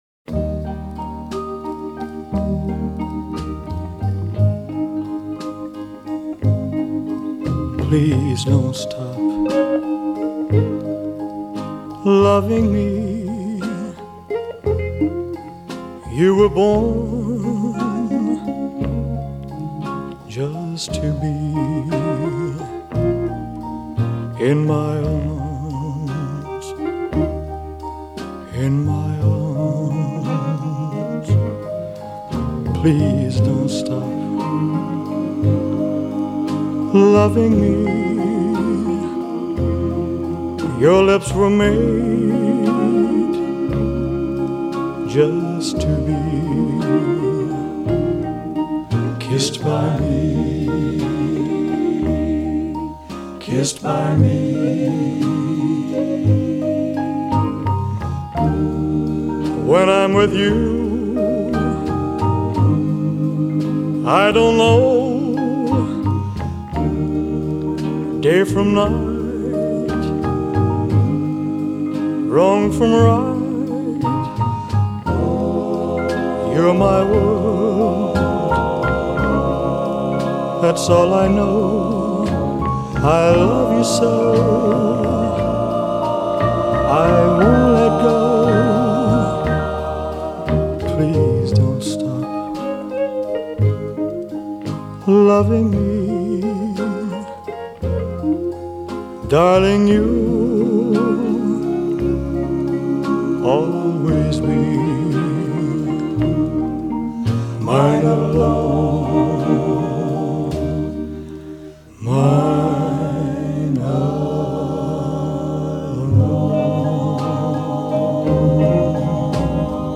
measured pitch-perfect harmonies
full-on ballad mode